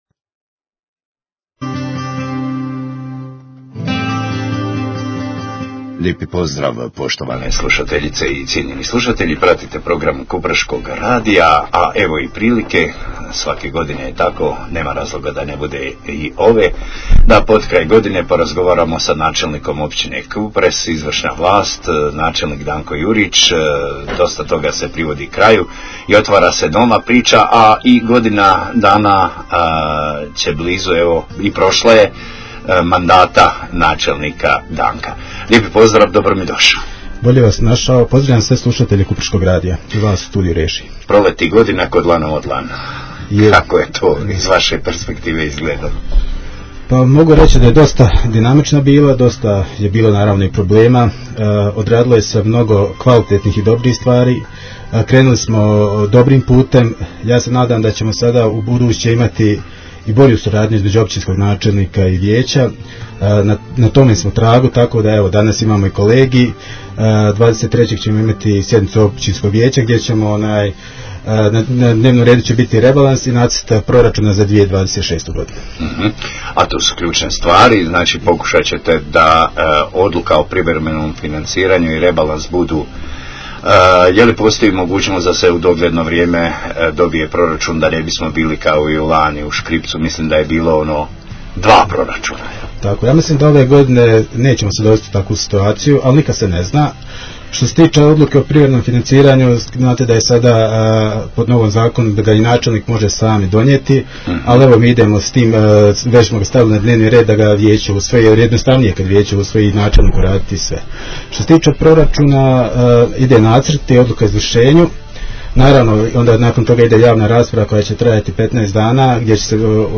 INTERVIEW: NAČELNIK JURIČ ZA KUPREŠKI RADIO - U 2026. godinu ulazimo s rekordnim proračunom i otvaranjem športske dvorane
Gostujući u programu Kupreškog radija, načelnik općine Kupres Danko Jurič osvrnuo se na proteklu godinu mandata, ali i najavio ambiciozne planove za 2026. godinu. Među ključnim projektima ističu se završetak športske dvorane, obnova vodovodne mreže i rekonstrukcija javnih objekata.